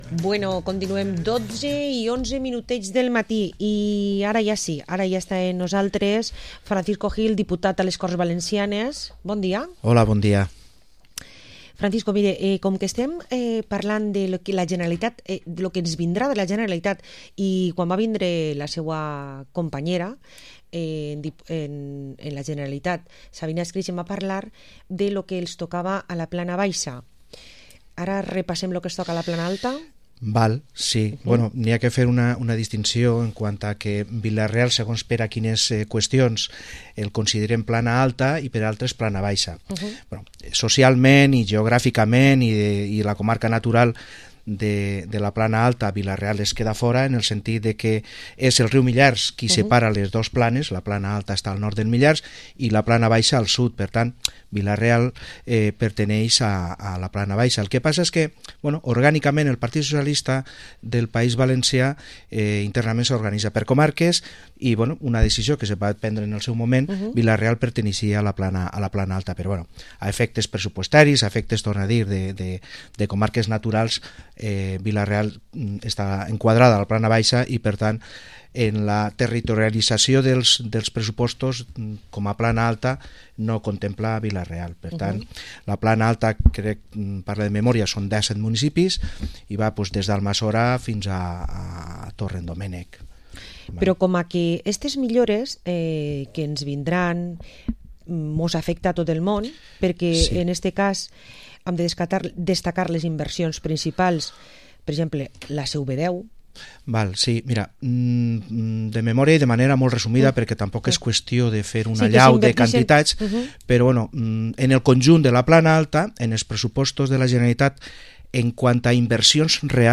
Entrevista al diputado autonómico y secretario general del PSPV-PSOE de Castelló, Francisco Gil